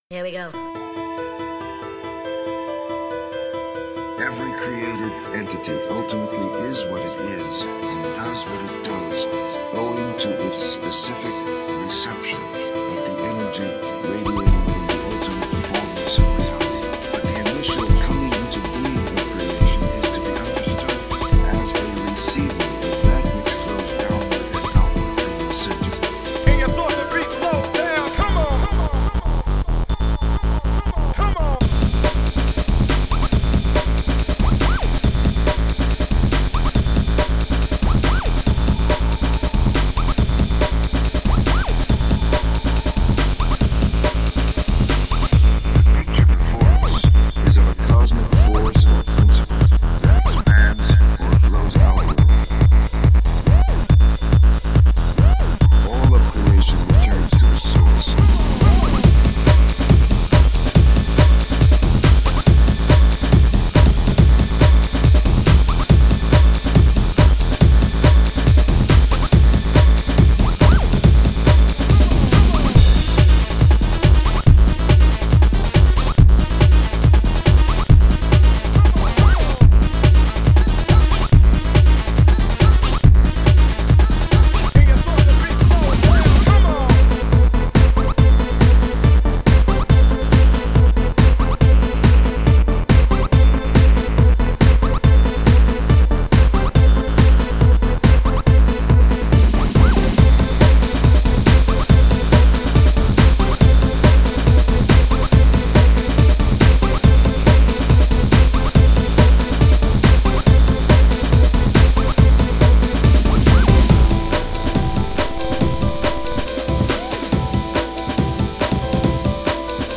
rave music
MY REMIX